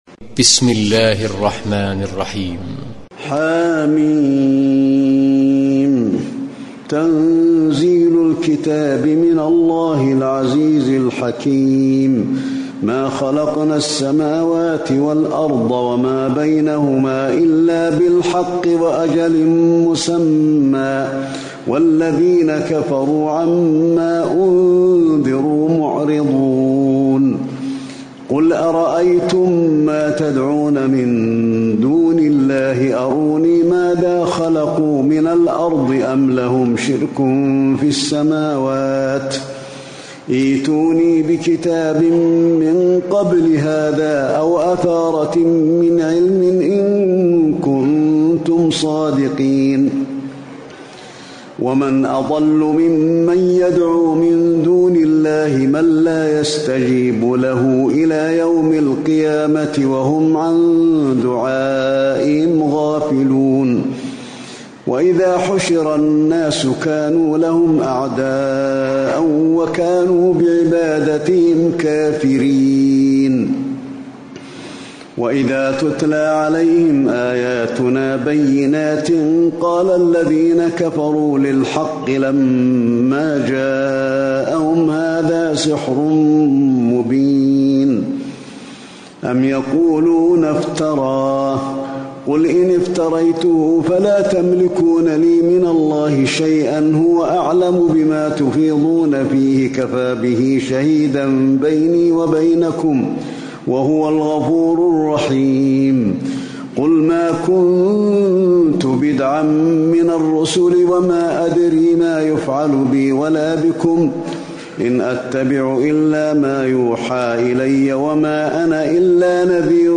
تراويح ليلة 25 رمضان 1437هـ من سور الأحقاف و محمد والفتح (1-19) Taraweeh 25 st night Ramadan 1437H from Surah Al-Ahqaf and Muhammad and Al-Fath > تراويح الحرم النبوي عام 1437 🕌 > التراويح - تلاوات الحرمين